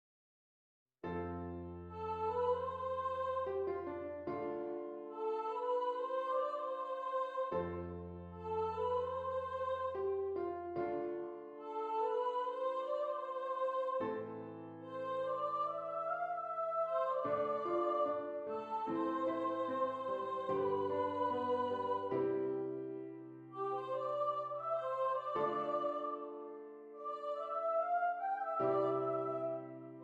C Major
Lento